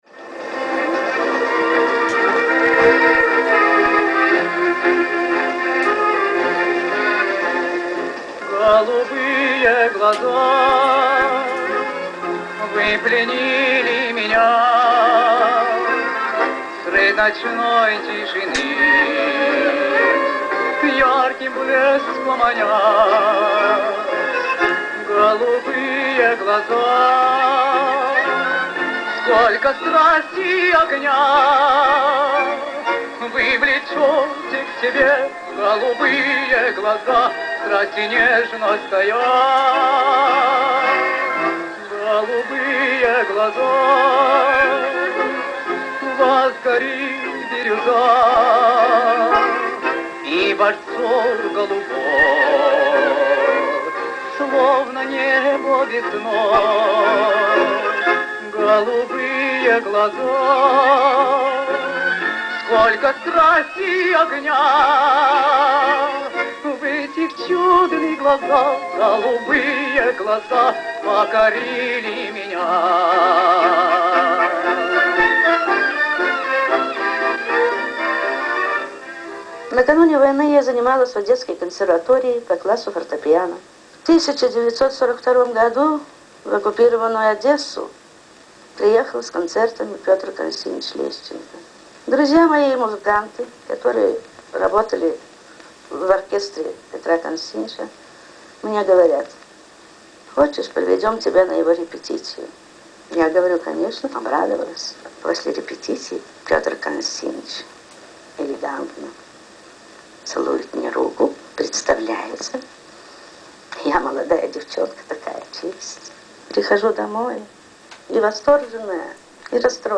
(Всё взято из архива СТАРОГО РАДИО).